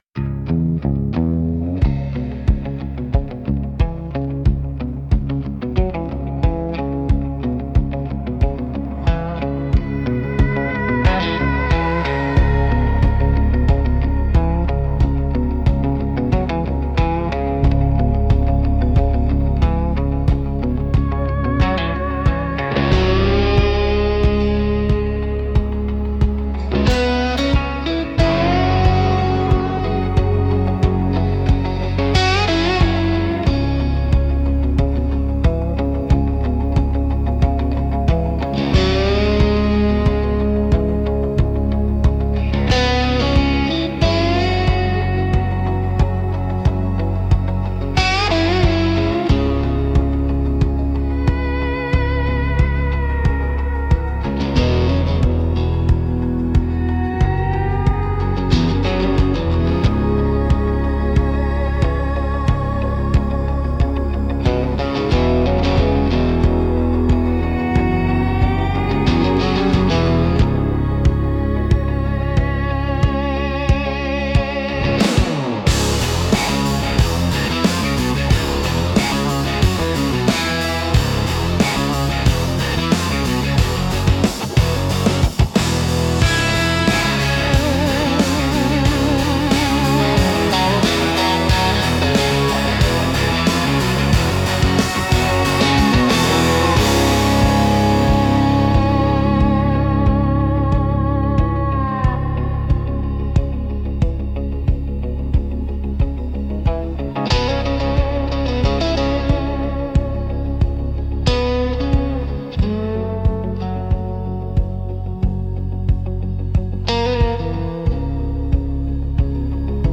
Instrumental - The Long Letting Go 4.29